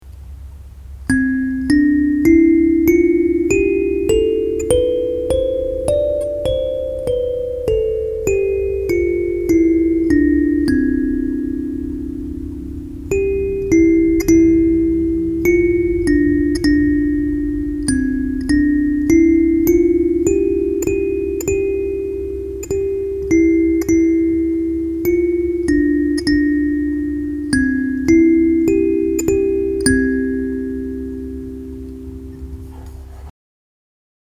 9 tons kalimba för barn stämd i c-dur.